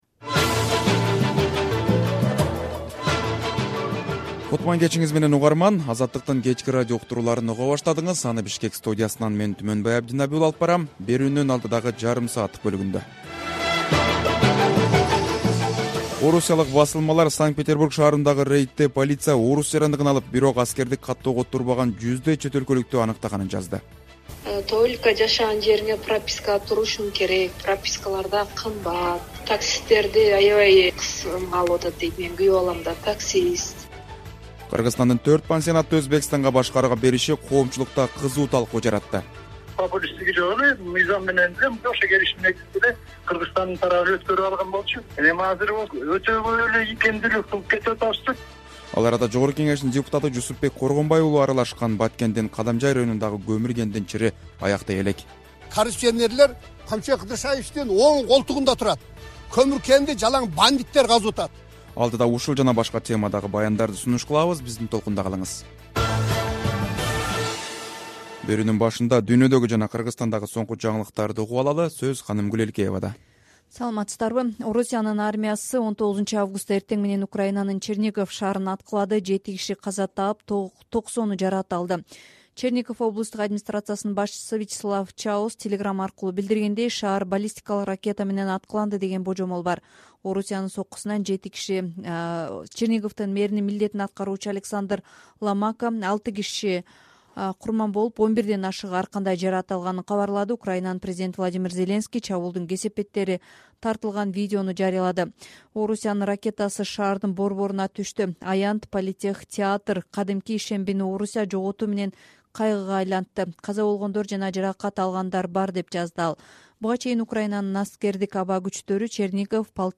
Кечки радио эфир | 19.08.2023 | Орусия Черниговдун борборуна сокку урду, курман болгондор бар